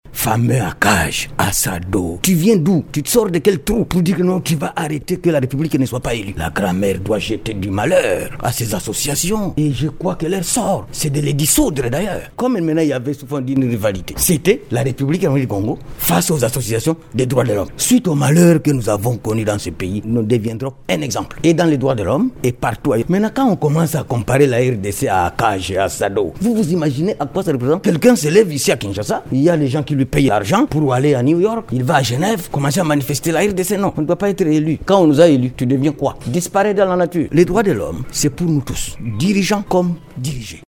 Les associations qui ont combattu la candidature de la RDC travaillent contre les intérêts de la République, a soutenu M. Bitakwira lors d’une conférence de presse à Kinshasa.